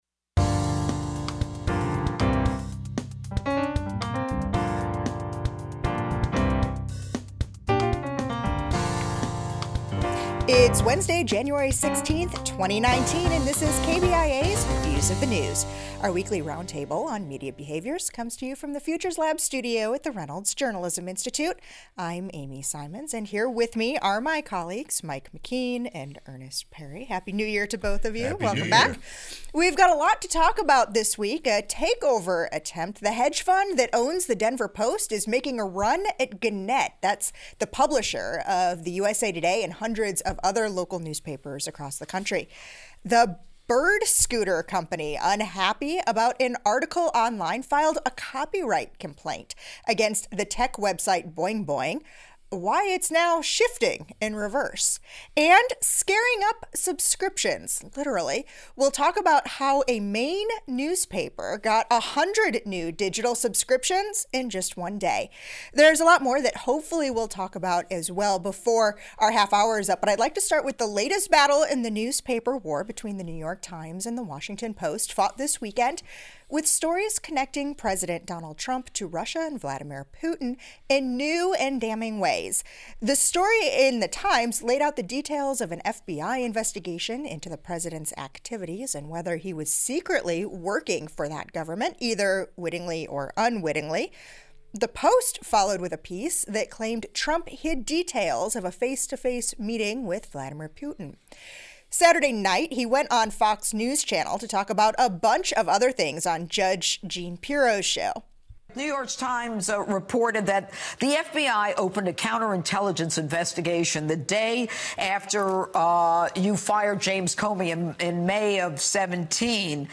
Media analysis from the Missouri School of Journalism.